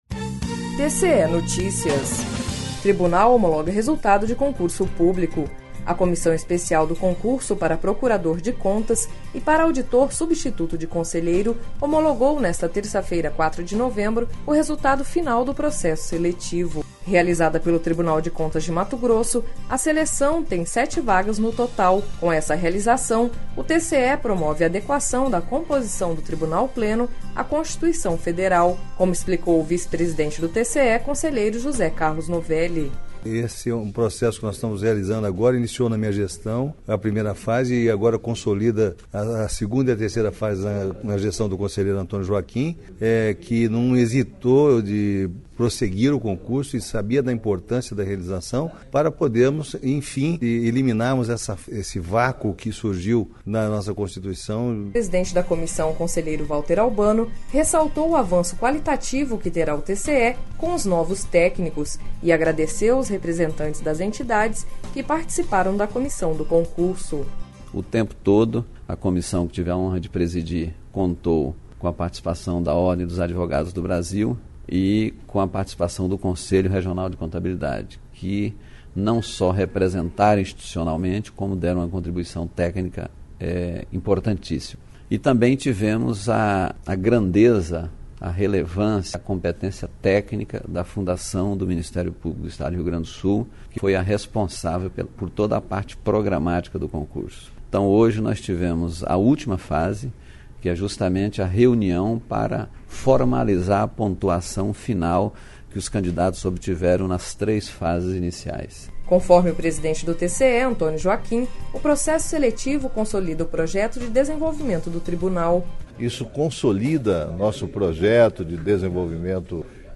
Sonora: José Carlos Novelli – conselheiro do TCE-MT